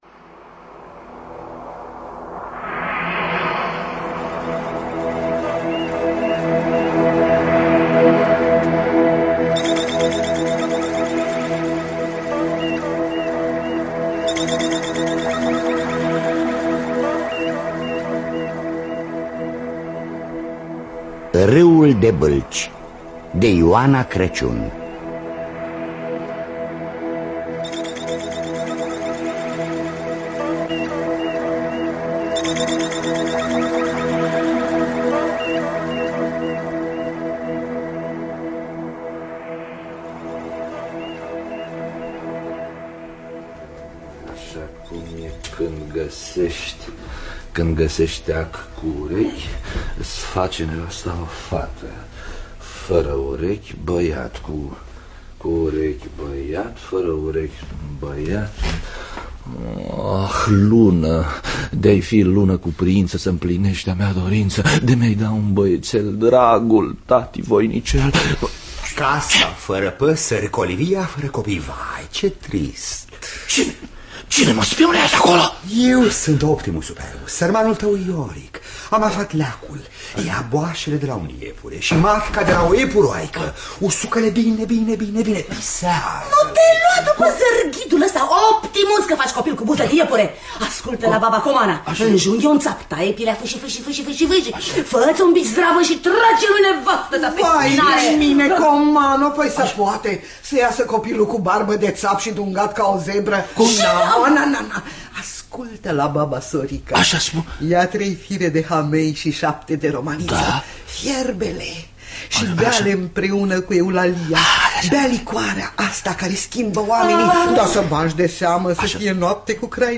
Râul de bâlci de Ioana Crăciun-Fischer – Teatru Radiofonic Online